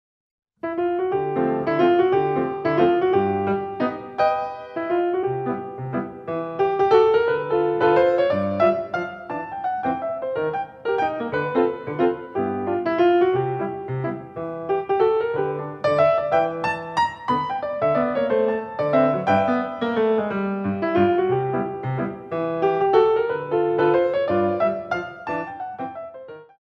Pirouettes On a Jazz Waltz